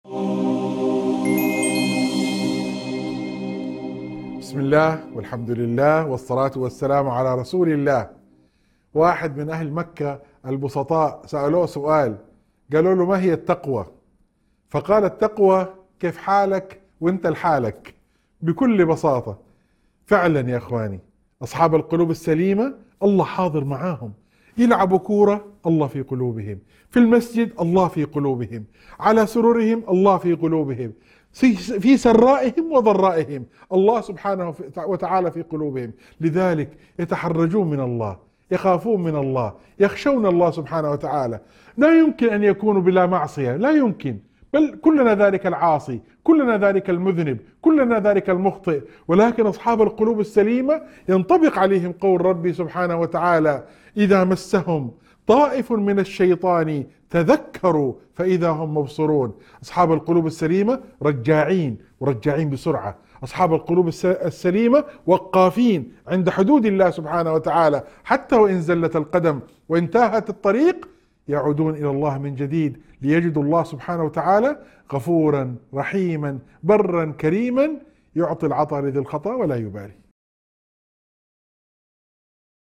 موعظة مؤثرة تتحدث عن صفات أصحاب القلوب السليمة الذين يستشعرون مراقبة الله في كل حال، مما يجعلهم يخشونه ويقفون عند حدوده. يشرح النص معنى التقوى الحقيقية ويحث على التوبة والرجوع إلى الله الكريم الغفور الرحيم.